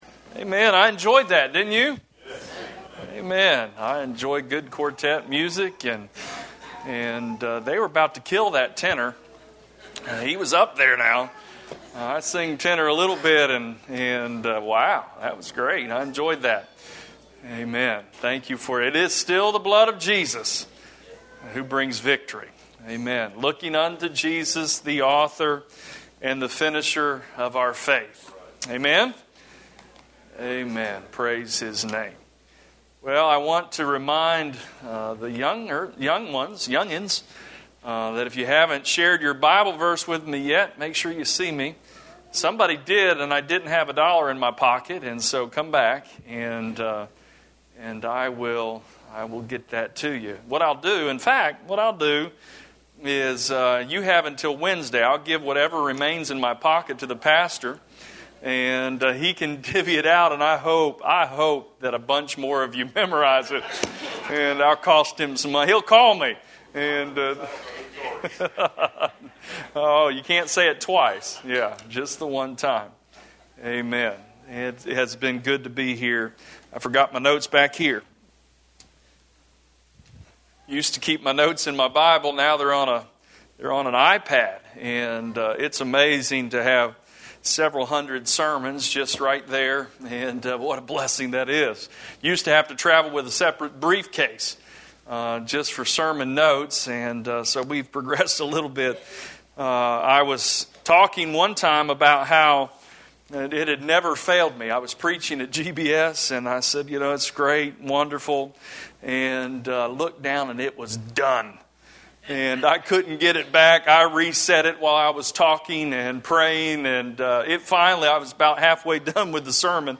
Series: Youth Revival 2019